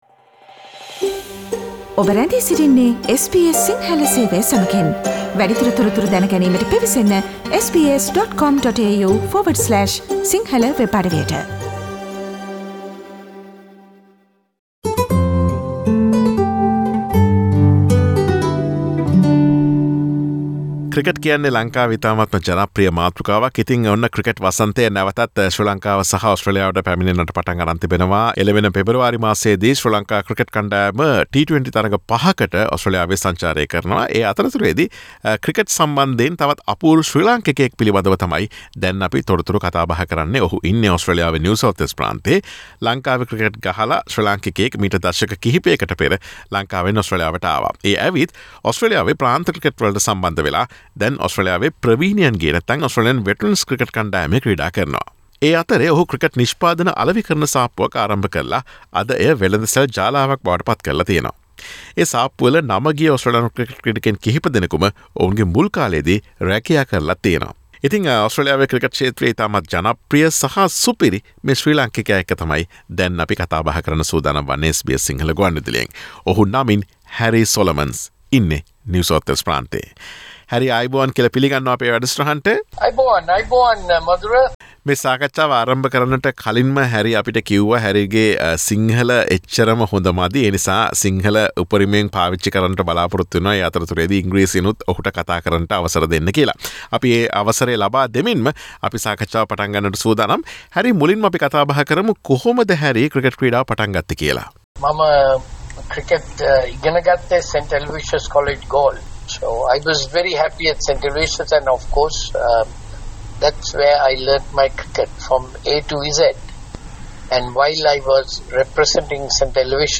SBS සිංහල ගුවන් විදුලිය ඔහු සමග සිදුකළ සාකච්ඡාවට සවන් දෙන්න.